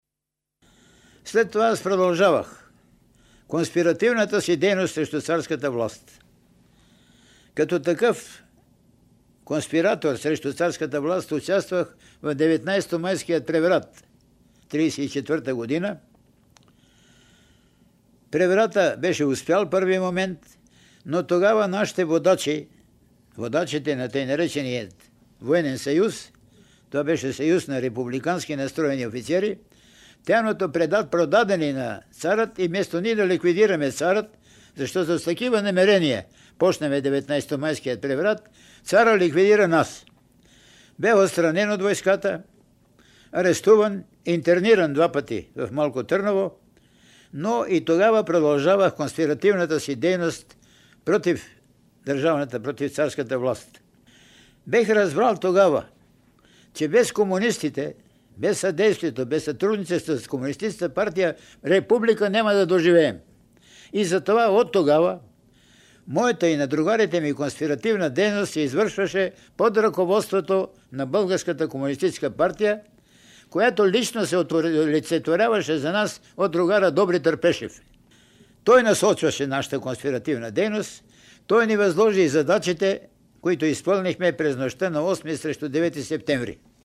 Предлагаме Ви няколко звукови документа, съхранени в Златния фонд на БНР, които представят аспекти от тези събития, както от преки свидетели и участници в тях, така и от дистанцията на времето и исторически поглед.